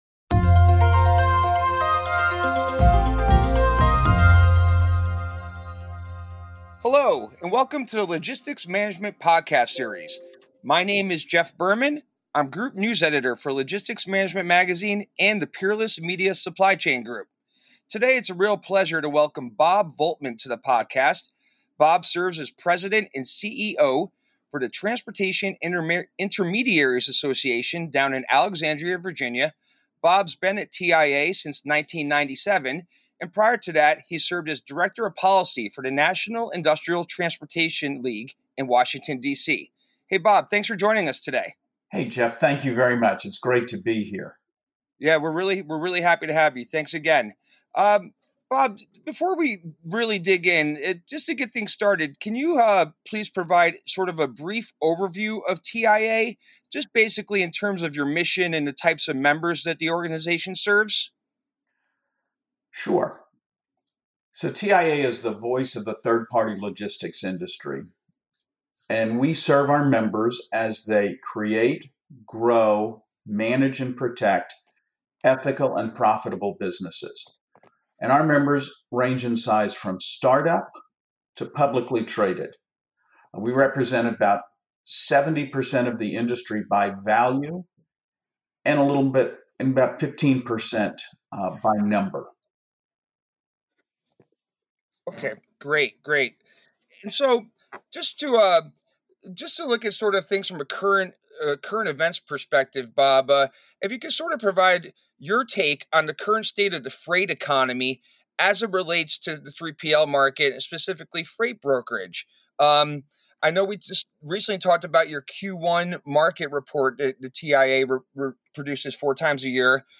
Join The Logistics Management Podcast as we dive into the world of logistics with in-depth conversations featuring industry professionals on the front lines of supply chain innovation.